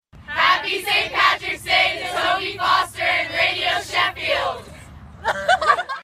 A St Patrick's Day blessing all the way from Louisiana for BBC Radio Sheffield listeners.